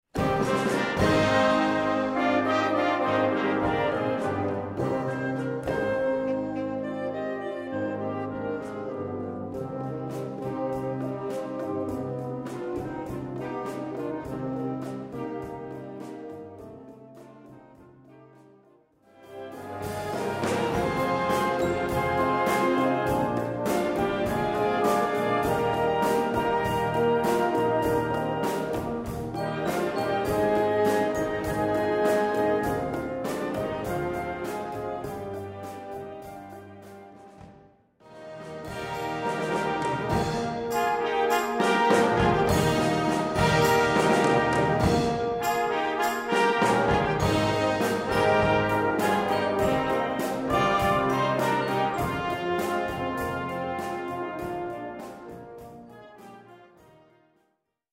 Gattung: Pop-Ballade
B-C Besetzung: Blasorchester Zu hören auf